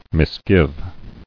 [mis·give]